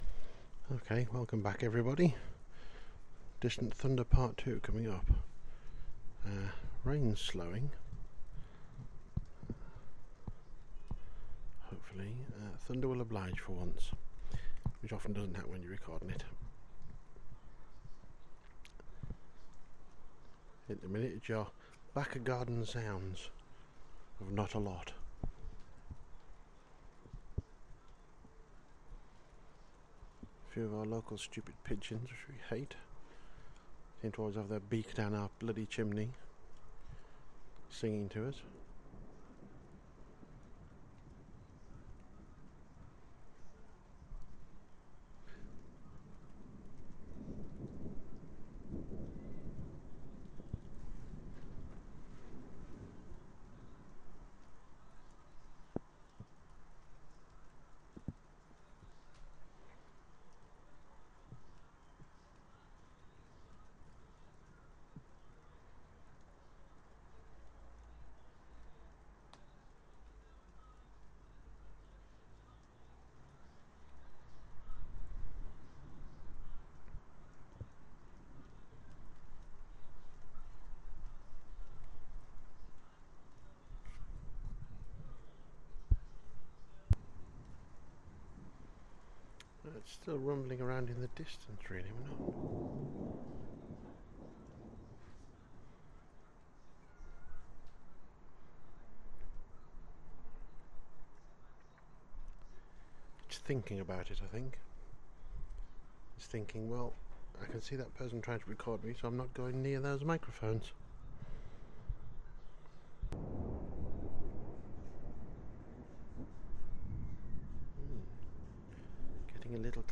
Distant thunder part two